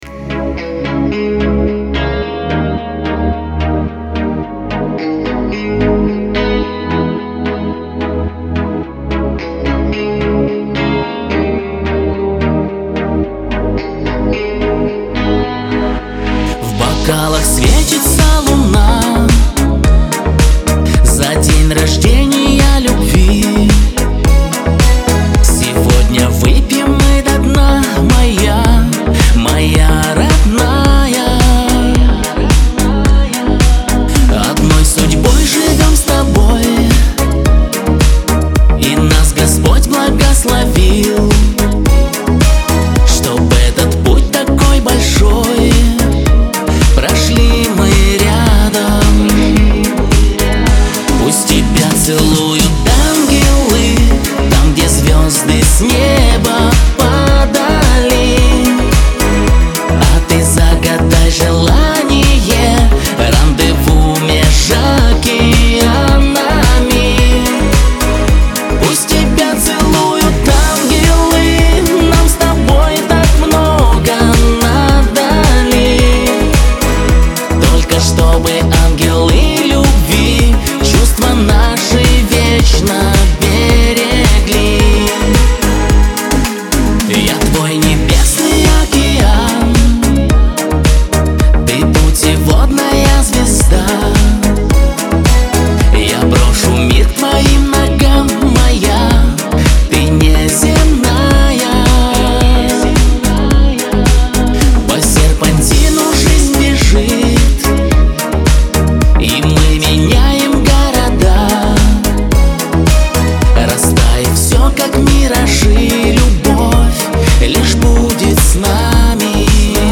эстрада
pop , диско